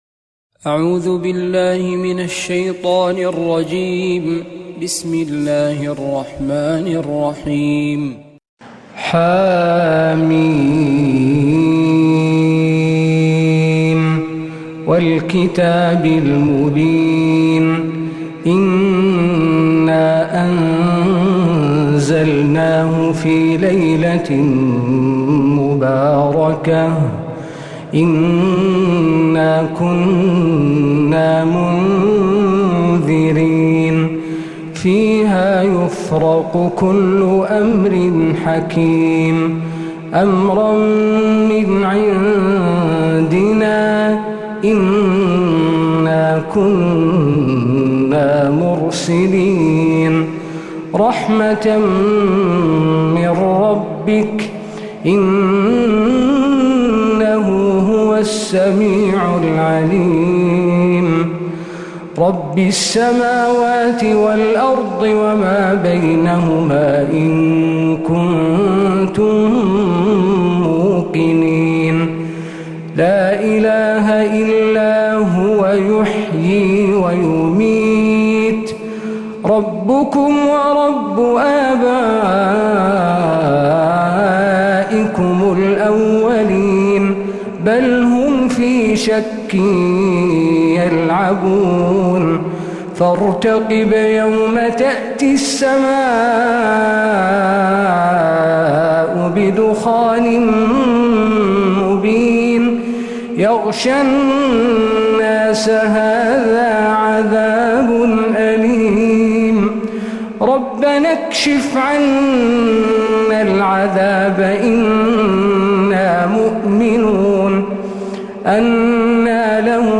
سورة الدخان كاملة من فجريات الحرم النبوي